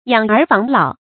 養兒防老 注音： ㄧㄤˇ ㄦˊ ㄈㄤˊ ㄌㄠˇ 讀音讀法： 意思解釋： 父母養育兒子；防備年老時無人照顧。